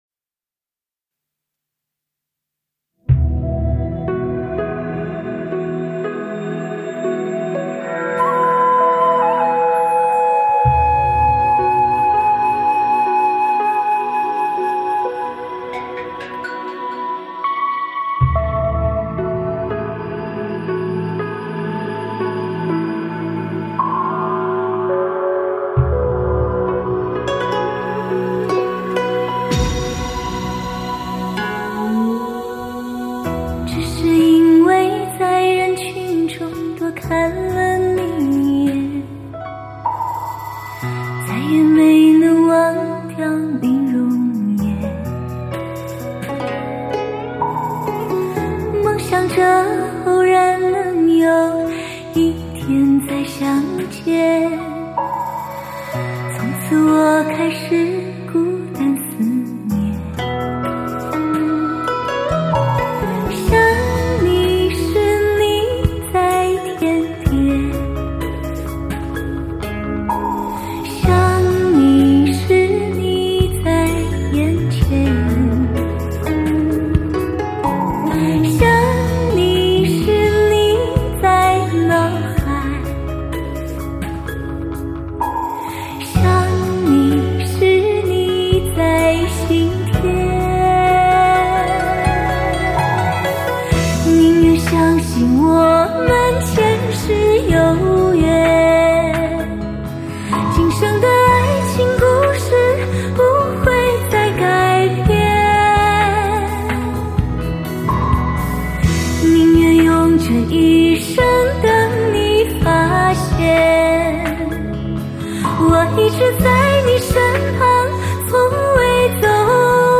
唱片类型：汽车音乐
一辑音效极致HIFI女声的汽车音响示范大碟。
极富视听效果的发烧靓声，德国版HD高密度24bit数码录音，顶级发烧器材专业监听精品CD，现今高
只有伤感的歌曲才能触动人心呀